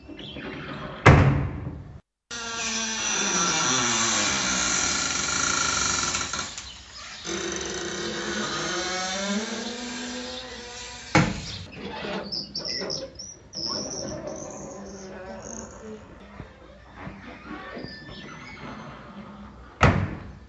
吱吱作响的钢门和木门（开+关）。
描述：打开+关闭吱吱作响的钢门和木门
Tag: 尖叫声 开放 船舶 吱吱 吱吱声 闹鬼 吱吱作响 吱吱 恐怖 开放 关闭 嘎嘎作响